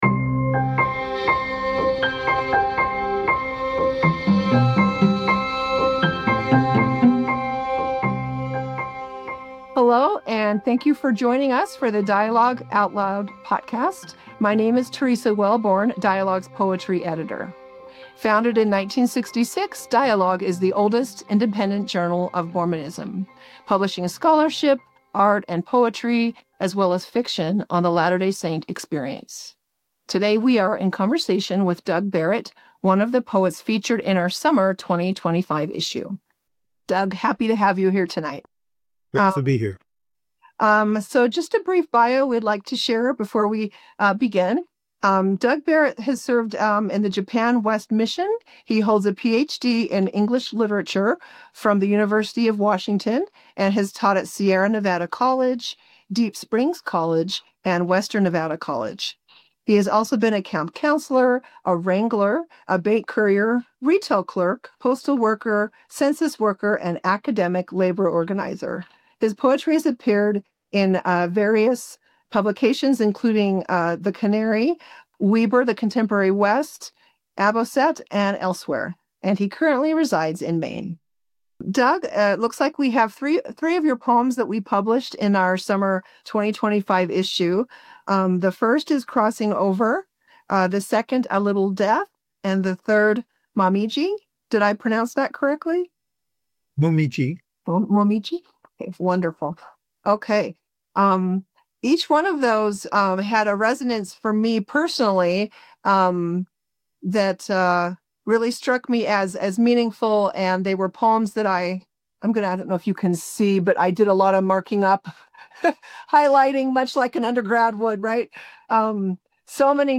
The Aesthetics of Poetics: A Conversation